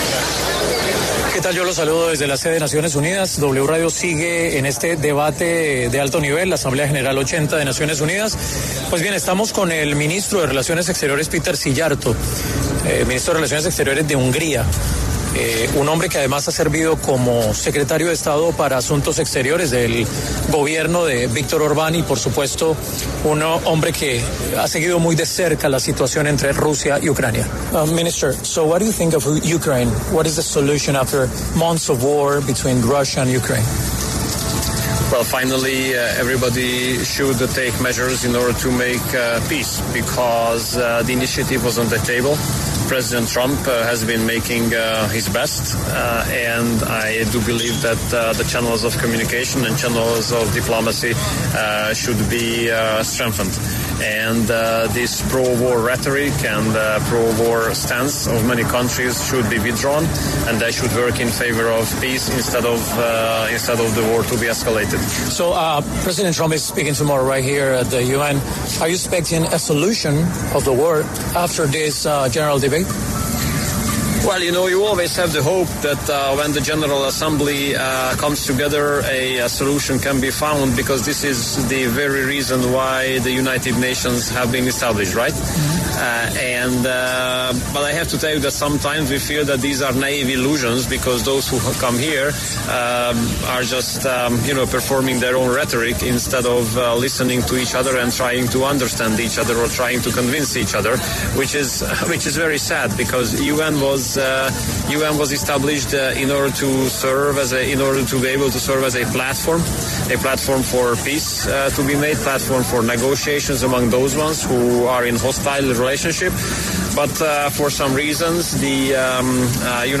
Péter Szijjártó, ministro de Asuntos Exteriores y Comercio de Hungría, en el marco de la Asamblea General 80 de Naciones Unidas, pasó por los micrófonos de La W y habló sobre la guerra en Ucrania.